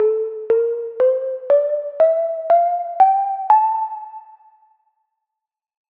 Phrygian
2025-kpop-scale-phry.mp3